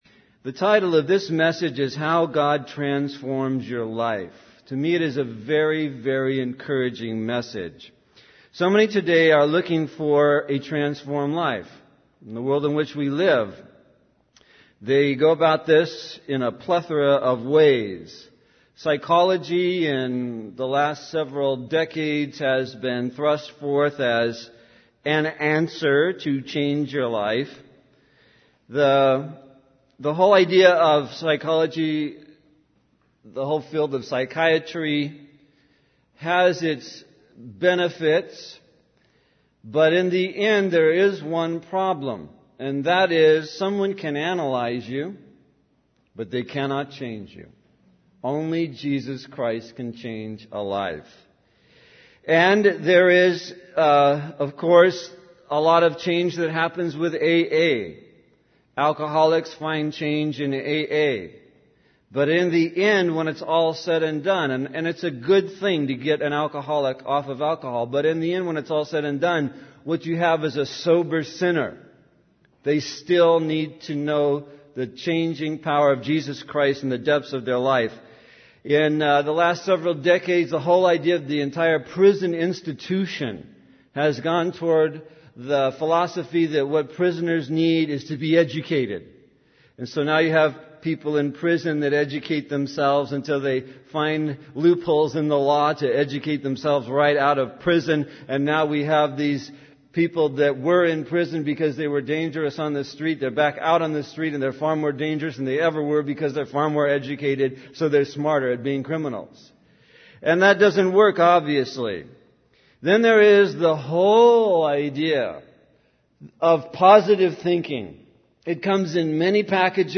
In this sermon, the speaker focuses on the transformation of Saul into Paul and how God can transform anyone's life. The process of transformation begins with faith in Jesus Christ, followed by the sending of faithful disciples to help and guide the individual. Fervent prayer is emphasized as a crucial component in this transformation.